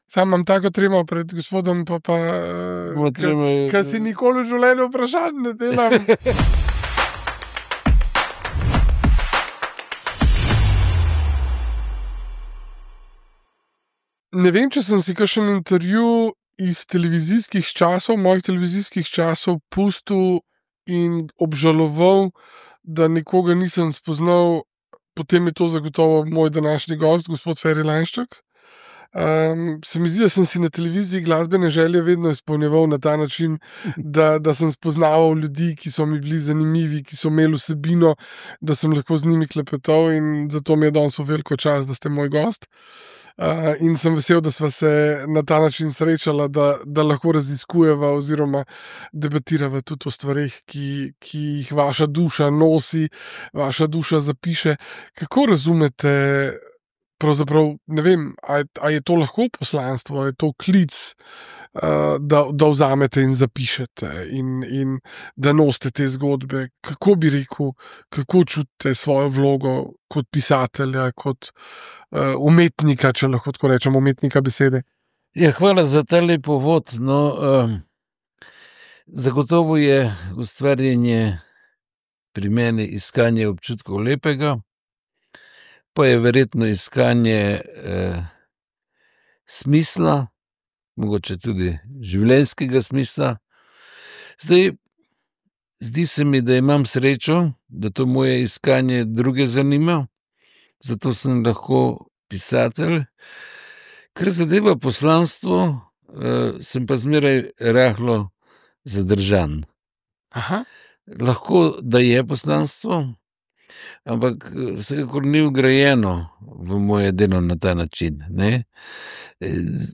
Kjer se energija sreča z besedoDobrodošli v podcast, kjer intervjuji niso le pogovor, ampak globoko raziskovanje misli, čustev in neizgovorjenih zgodb.